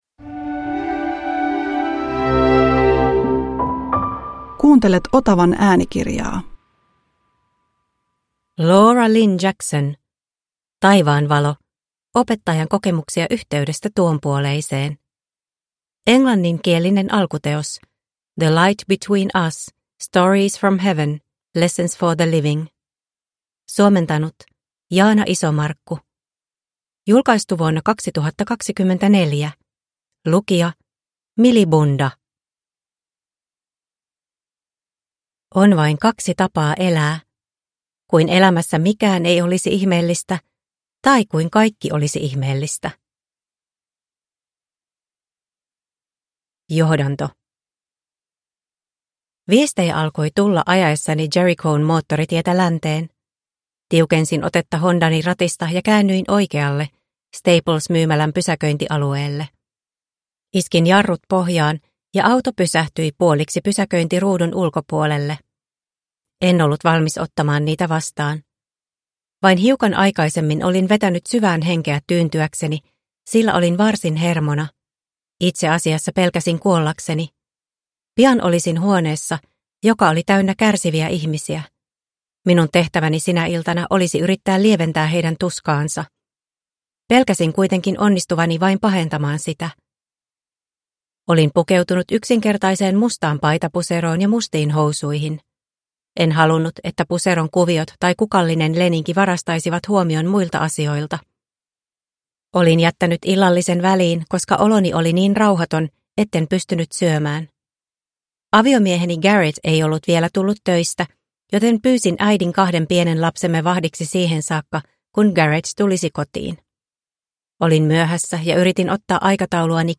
Taivaan valo (ljudbok) av Laura Lynne Jackson